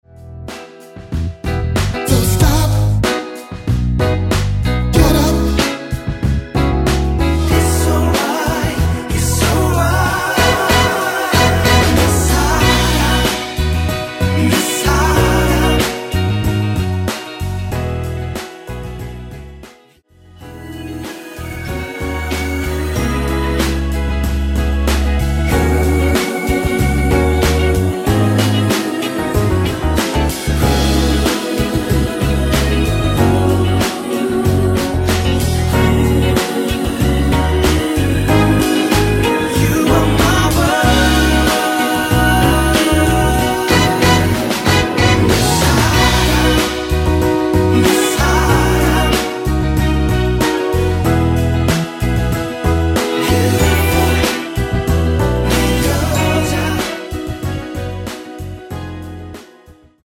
엔딩이 페이드 아웃이라 라이브 하기 좋게 원곡 3분 6초쯤에서 엔딩을 만들었습니다.(원키 미리듣기 참조)
앞부분30초, 뒷부분30초씩 편집해서 올려 드리고 있습니다.
중간에 음이 끈어지고 다시 나오는 이유는